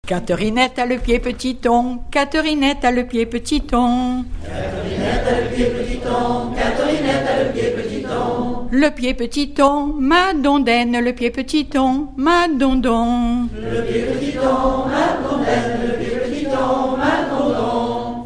Mémoires et Patrimoines vivants - RaddO est une base de données d'archives iconographiques et sonores.
Genre énumérative
Pièce musicale inédite